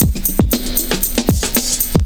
ELECTRO 13-R.wav